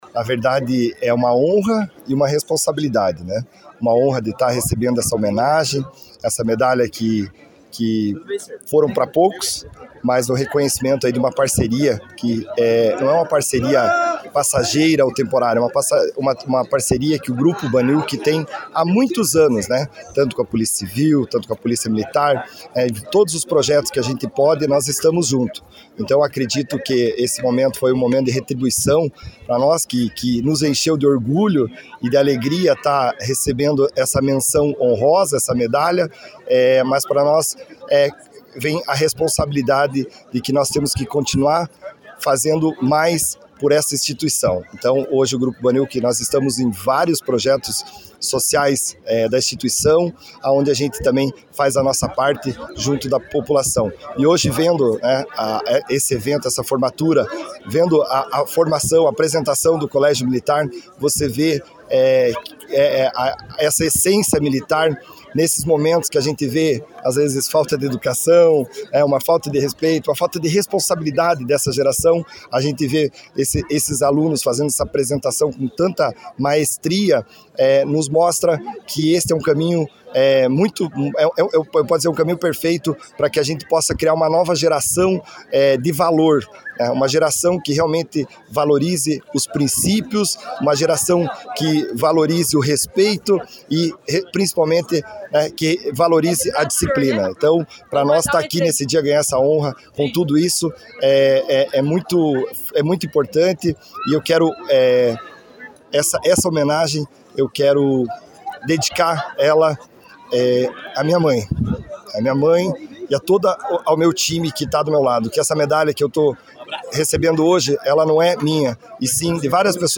Em União da Vitória, a data foi celebrada com uma solenidade especial realizada nas dependências do 27º Batalhão de Polícia Militar, reunindo autoridades civis e militares, além de familiares e convidados.